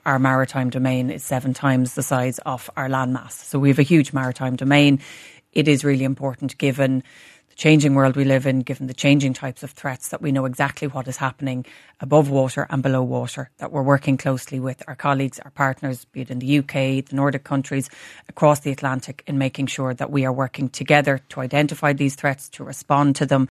Defence Minister Helen McEntee says the strategy will see the Irish navy working with partners around the world: